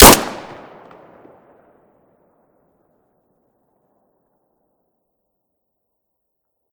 glock17_shoot.ogg